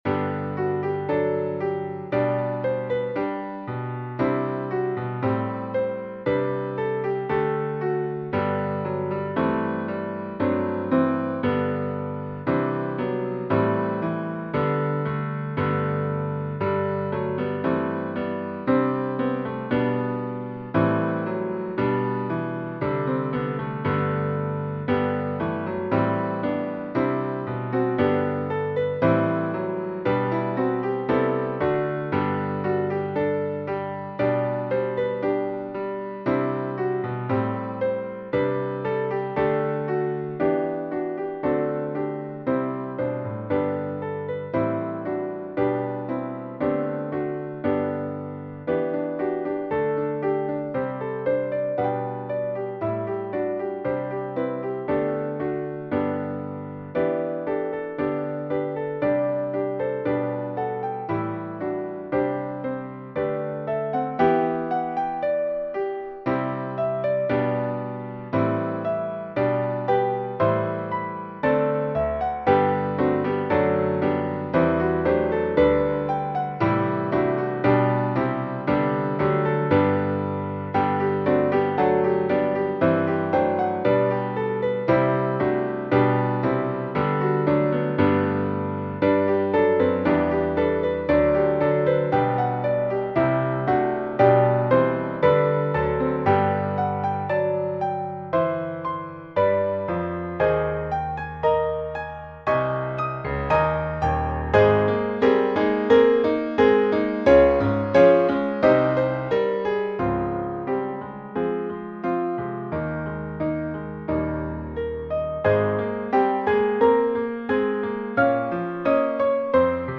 For the Beauty of the Earth--SATB with piano accompaniment. Hymplicity style. I wrote an additional verse about the Savior.
Voicing/Instrumentation: SATB We also have other 48 arrangements of " For the Beauty of the Earth ".